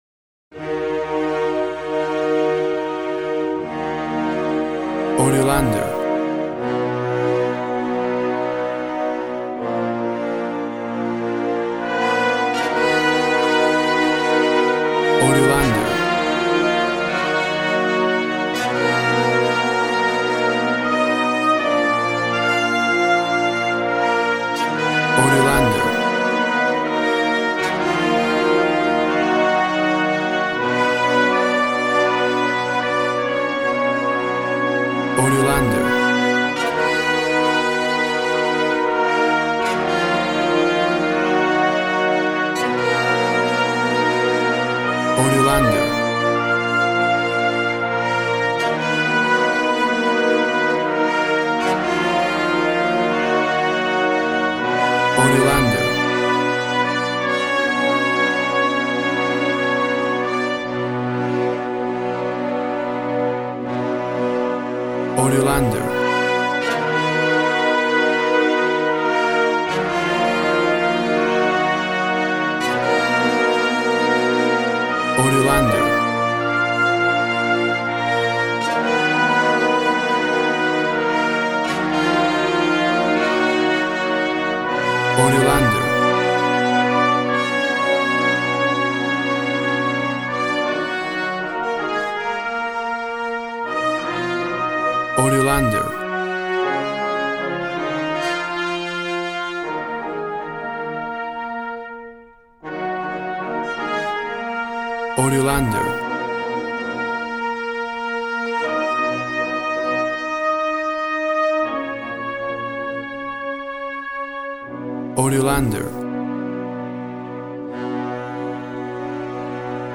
Epic tragedy and sounds of the birth of a figh.
Tempo (BPM) 80